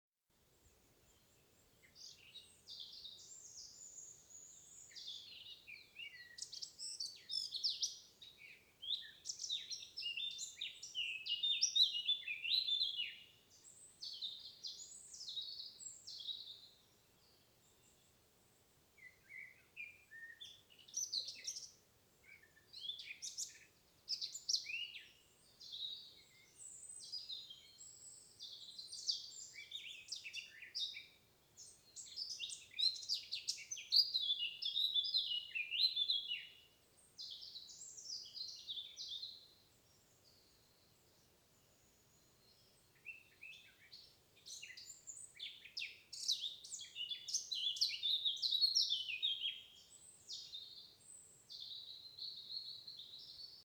Птицы -> Славковые ->
черноголовая славка, Sylvia atricapilla
СтатусПоёт